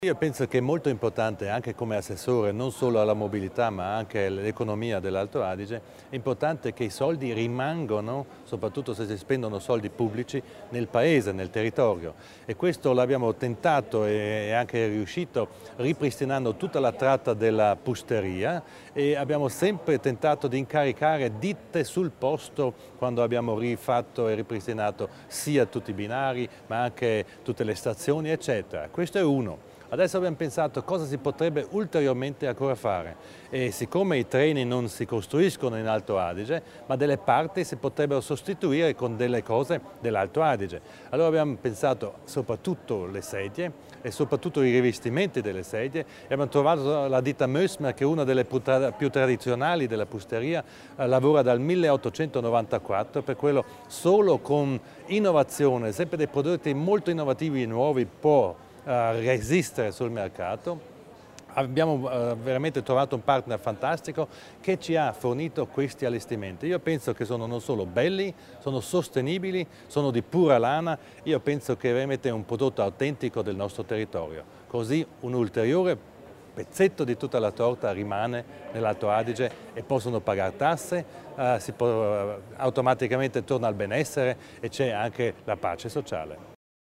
L'Assessore Widmann illustra i vantaggi della collaborazione con una ditta locale
Nel corso del suo intervento di presentazione dei nuovi rivestimenti dei sedili dei nuovi treni regionali l'assessore provinciale alla mobilità, Thomas Widmann ha sottolineato il ruolo trainante della ferrovia della Val Pusteria anche l'intero settore economico.